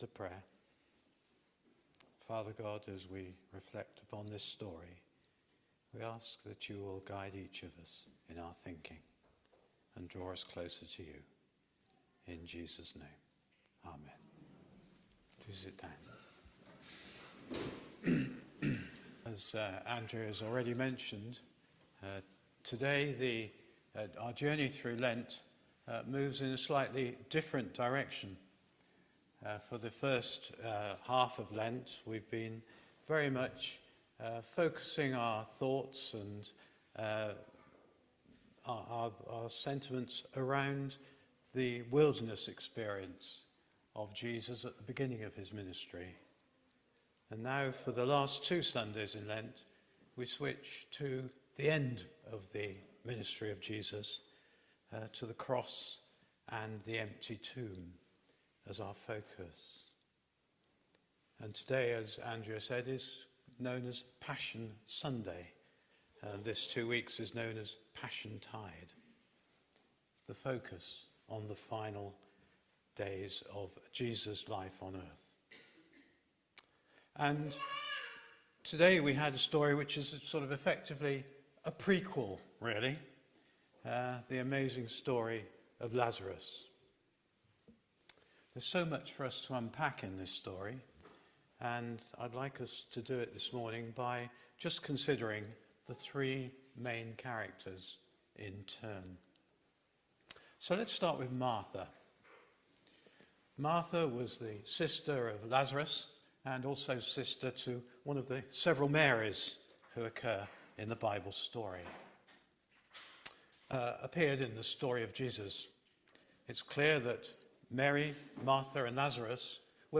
HomeSermonsThere is a time for weeping